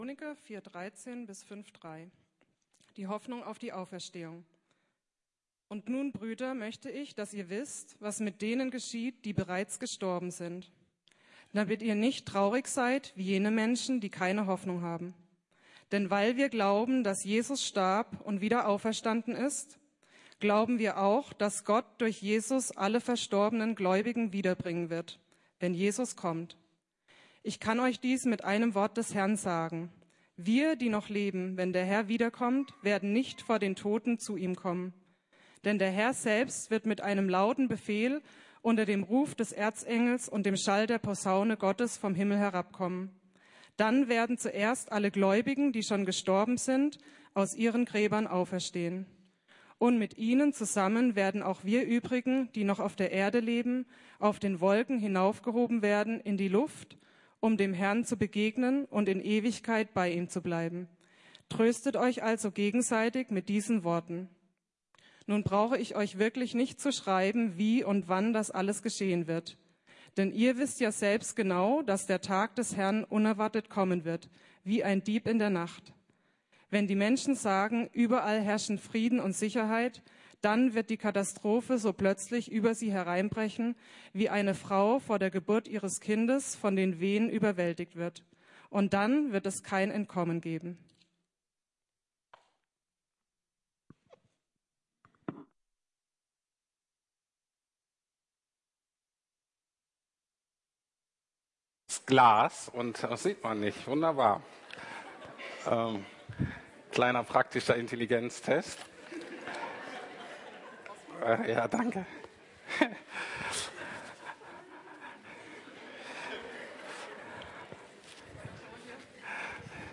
Das Beste kommt zum Schluss ~ Predigten der LUKAS GEMEINDE Podcast